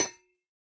Minecraft Version Minecraft Version 1.21.5 Latest Release | Latest Snapshot 1.21.5 / assets / minecraft / sounds / block / copper_grate / break4.ogg Compare With Compare With Latest Release | Latest Snapshot
break4.ogg